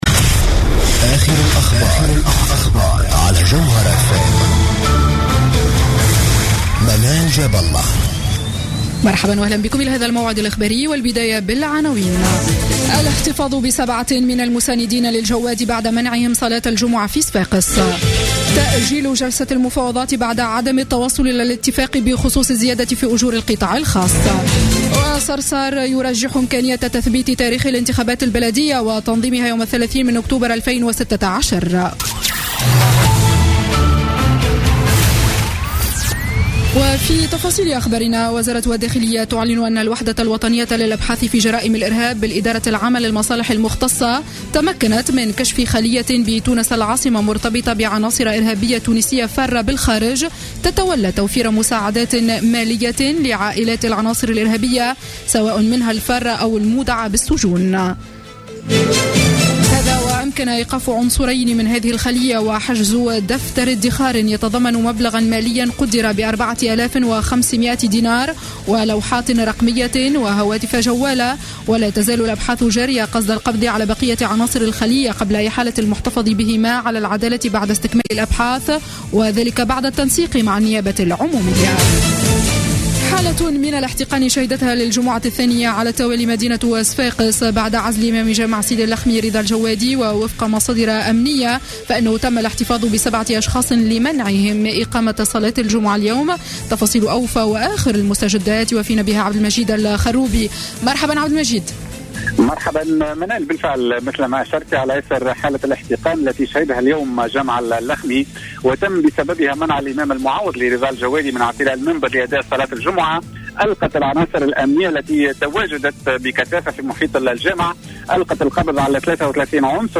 نشرة أخبار السابعة مساء ليوم الجمعة 23 أكتوبر 2015